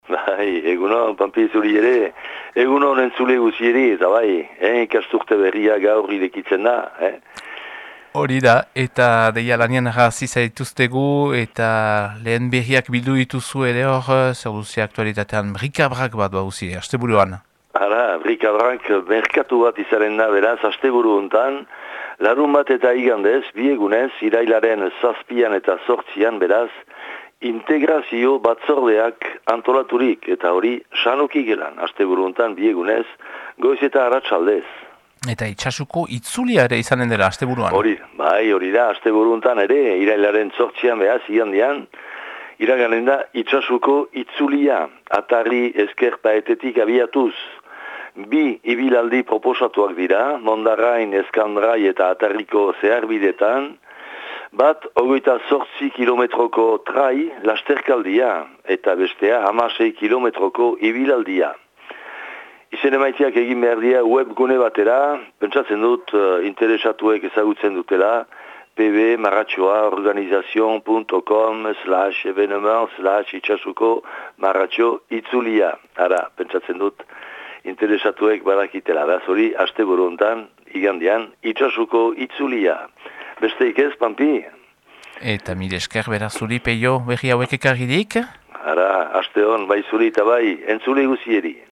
Irailaren 2ko Itsasuko berriak